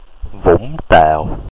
Vũng Tàu (Hanoi accent: [vuŋm˧ˀ˥ taːw˨˩] , Saigon accent: [vuŋm˧˩˧ taːw˨˩]